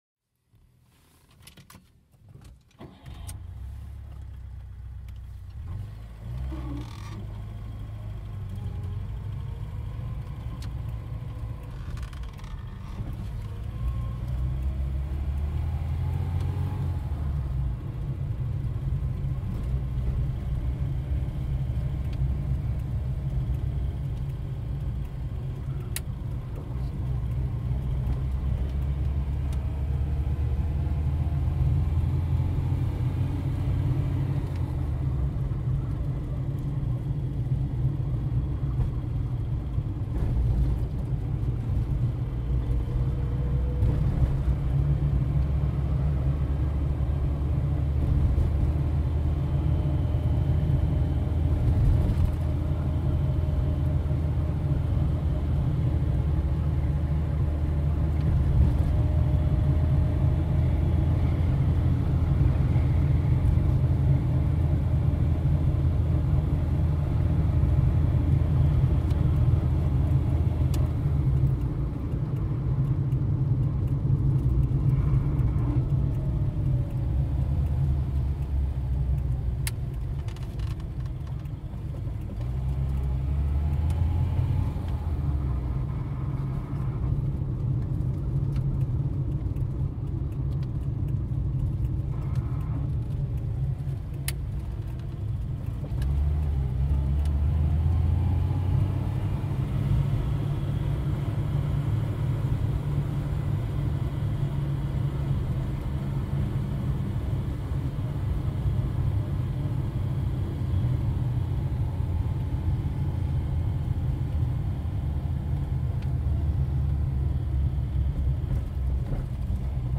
Звук езды на Жигулях Копейка по городским улицам запись из салона